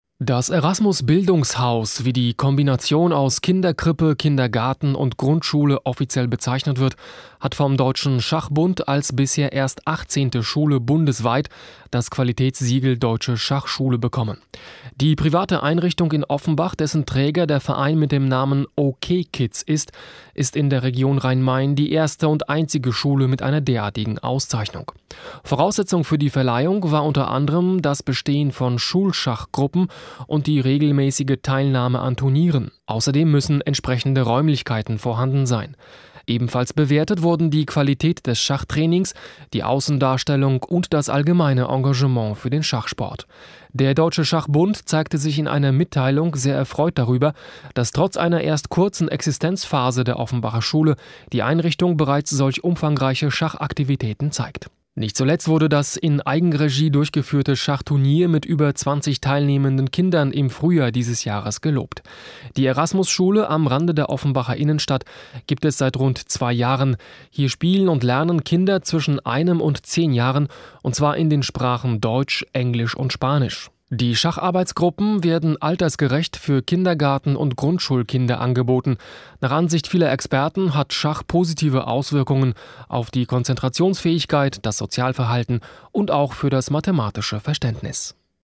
Im Radio wird die Verleihung des Qualitätssiegels „Deutsche Schachschule“ an die Erasmus-Grundschule angekündigt.